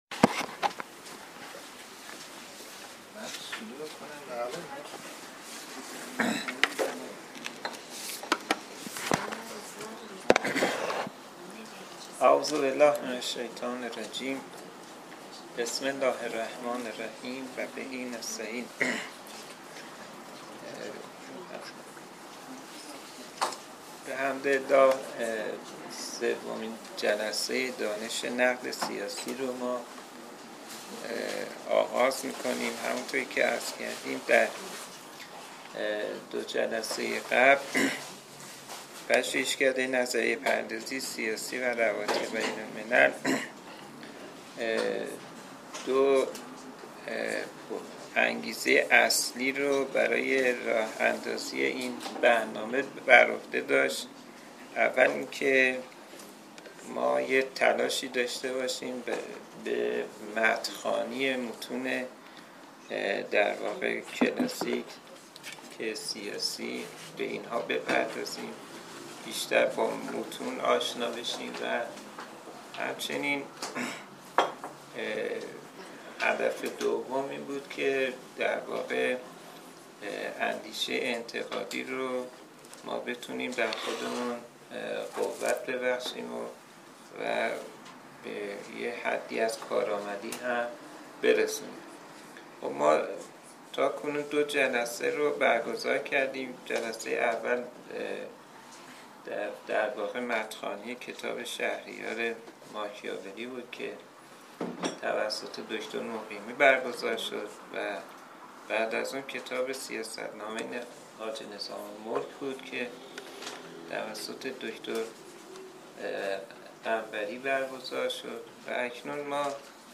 پژوهشکده نظریه پردازی سیاسی و روابط بین الملل برگزار می کند: خوانش کتاب تذکره شاه طهماسب زمان: سه شنبه 10 اردیبهشت 98 ساعت: 10 تا...
پژوهشکده نظریه پردازی سیاسی و روابط بین الملل برگزار می کند: